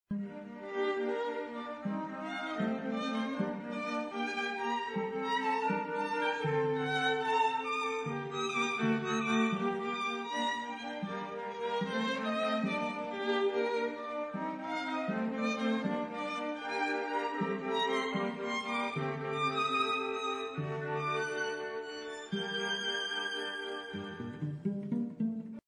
Aquí tienes cuatro enlaces que te muestran una parte de dos sinfonías y como la exposición al ruido nos reduce nuestros niveles de audición: